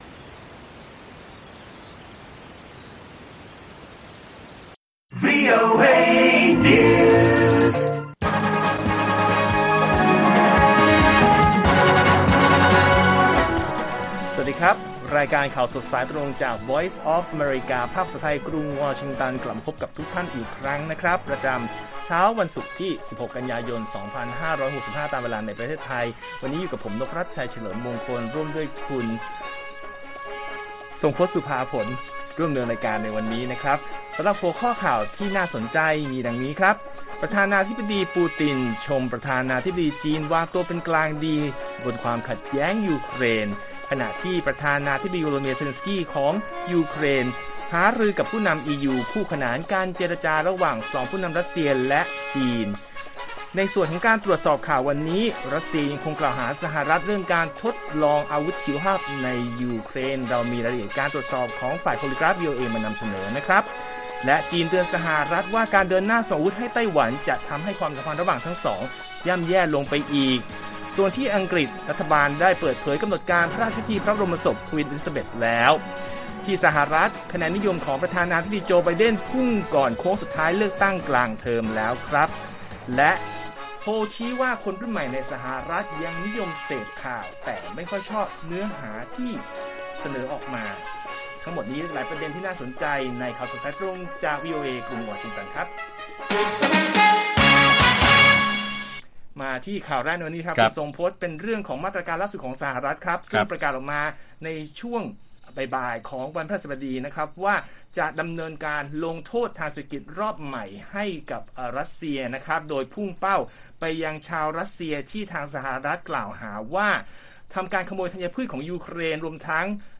ข่าวสดสายตรงจากวีโอเอไทย 6:30 – 7:00 น. วันที่ 16 ก.ย. 65